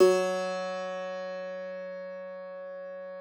53l-pno08-F1.aif